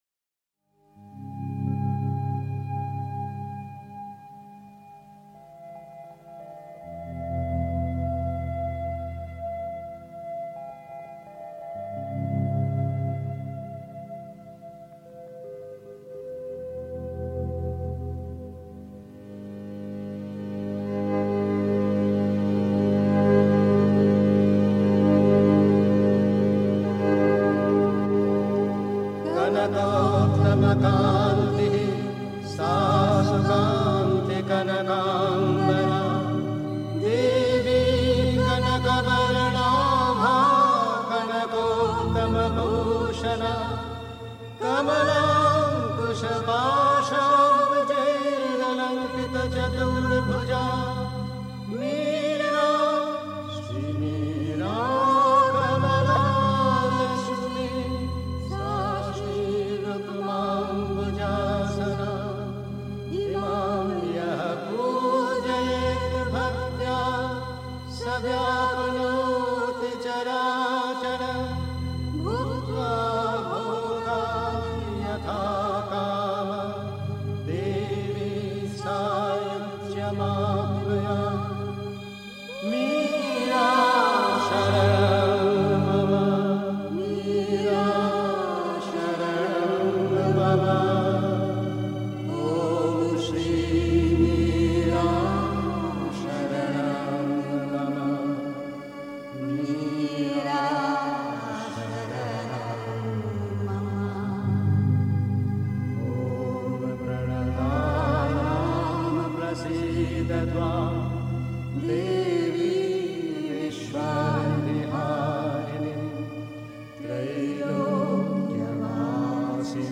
Pondicherry. 2. Ein Ruf für den Pfad (Die Mutter, The Sunlit Path) 3. Zwölf Minuten Stille.